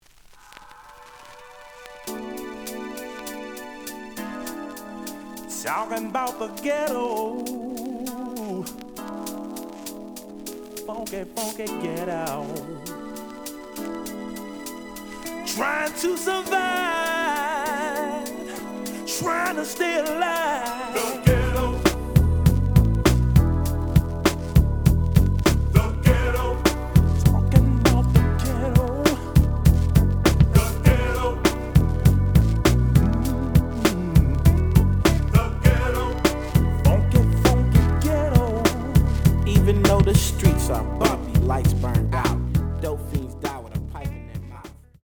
The audio sample is recorded from the actual item.
●Genre: Hip Hop / R&B
Slight noise on A side.